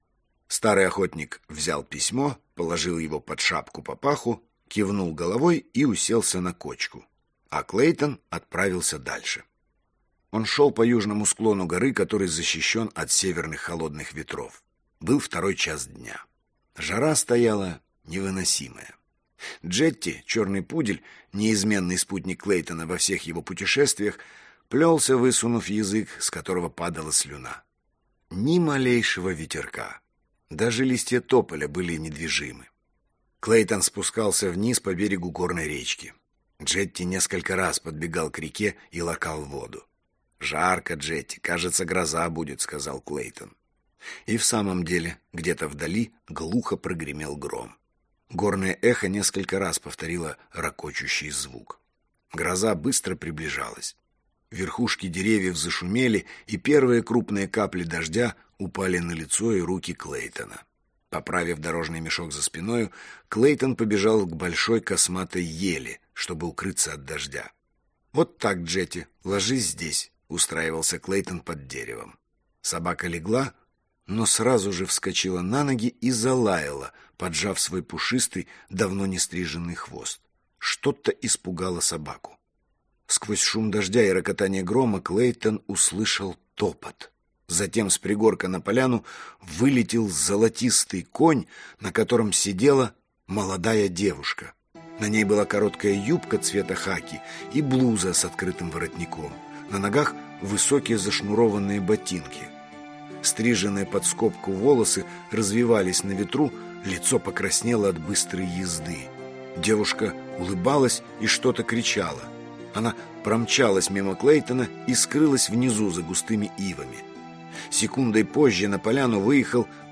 Аудиокнига Золотая гора | Библиотека аудиокниг